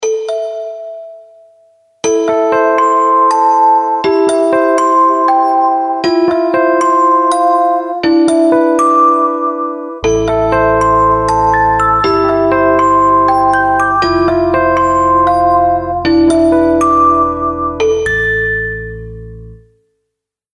| pop melody |